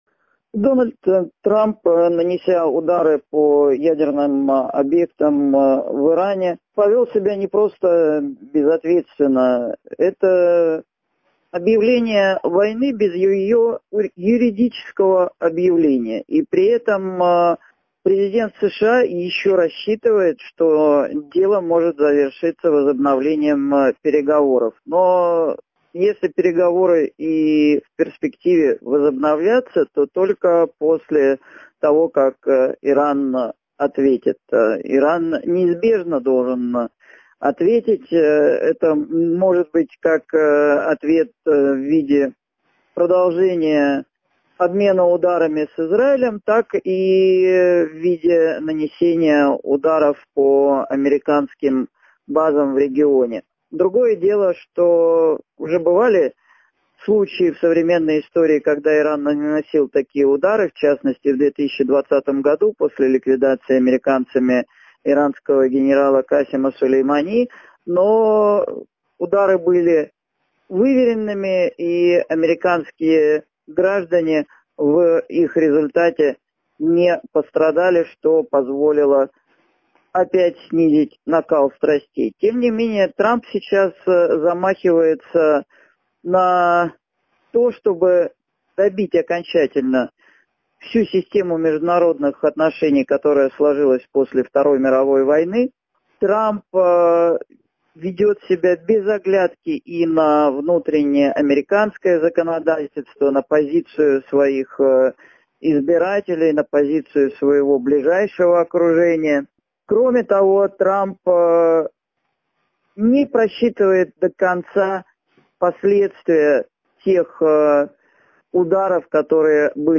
ГЛАВНАЯ > Актуальное интервью